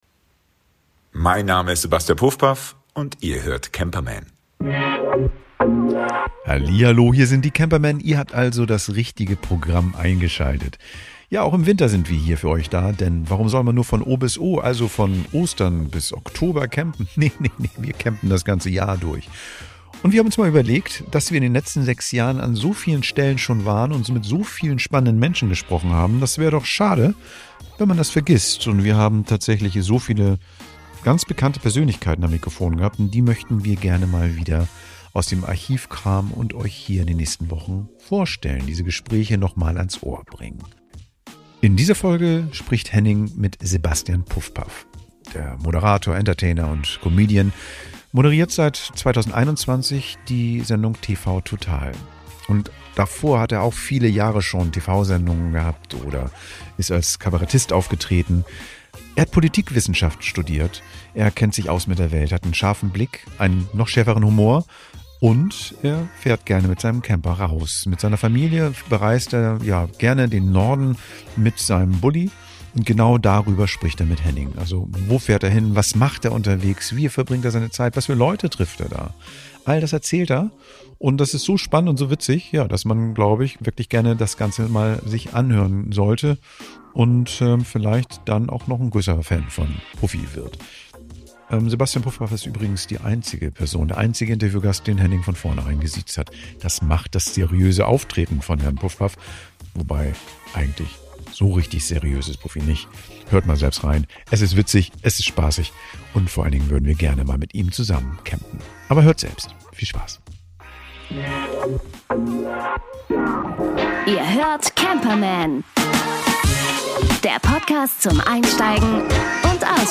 In dieser Folge unserer Artist-Specials veröffentlichen wir unser Interview mit dem Moderator Sebastian Pufpaff.